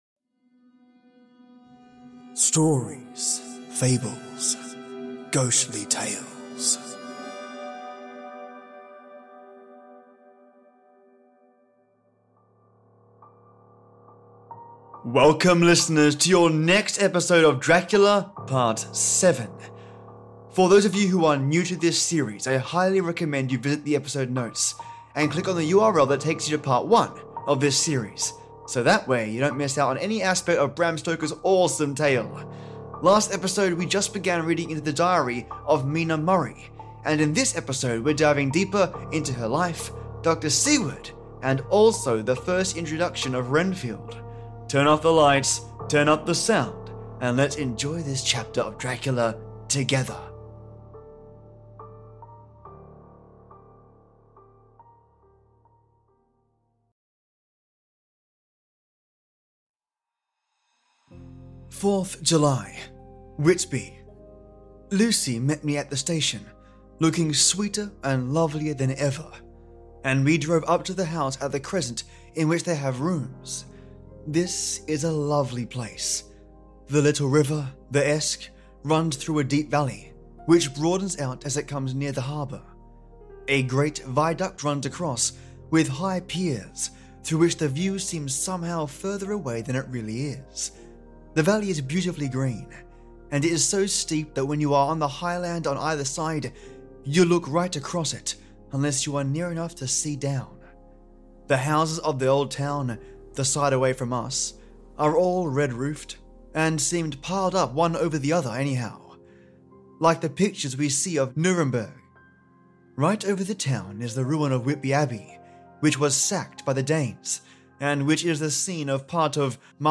567: Dracula by Bram Stoker | Audio Book Part 7 | Mina, Dr Seward, and Renfield 🧛‍♂